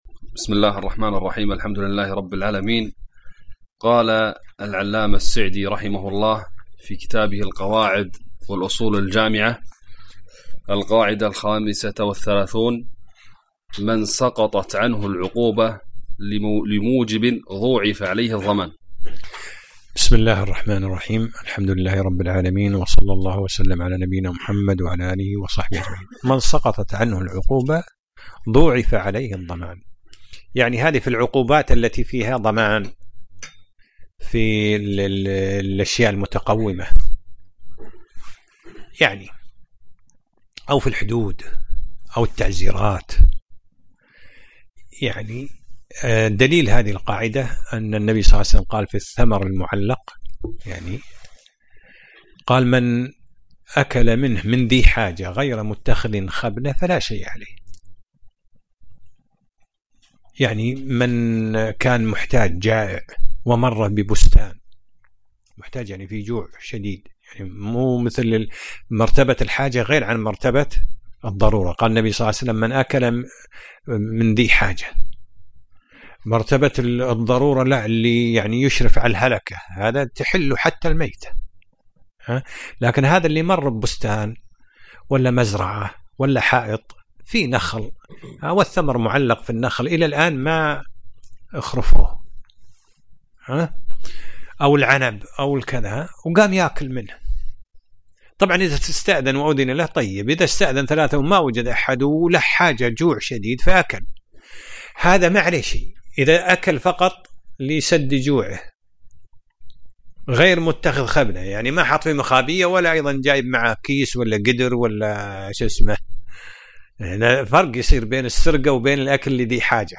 الدرس الحادي عشر : من القاعده 35 إلى القاعده 38